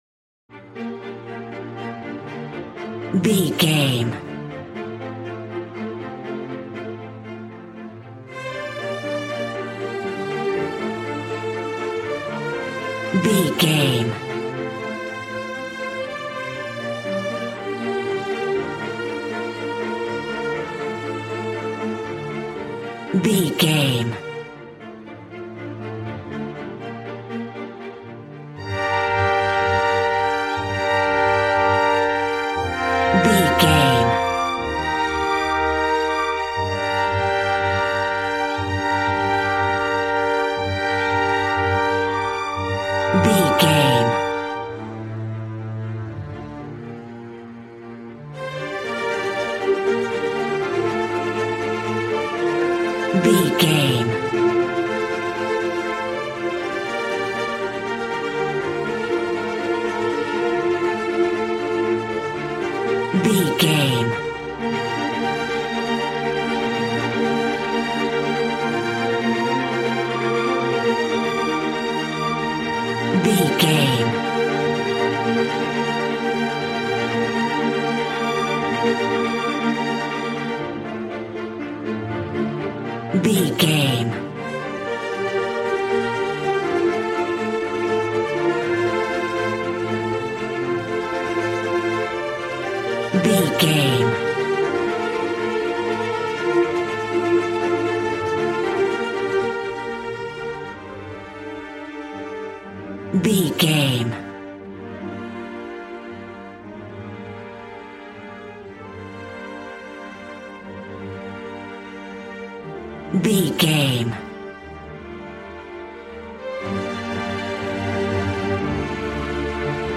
A classical music mood from the orchestra.
Regal and romantic, a classy piece of classical music.
Ionian/Major
B♭
regal
cello
violin
strings